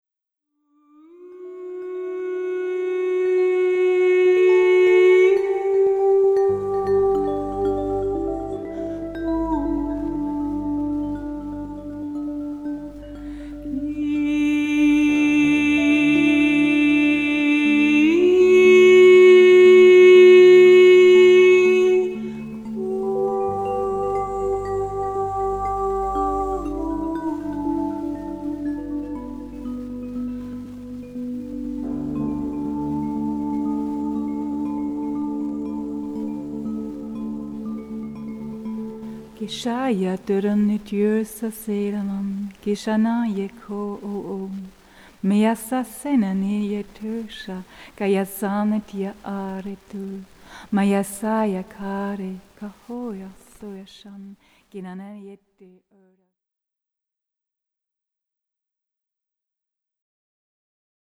Momentum-Aufnahmen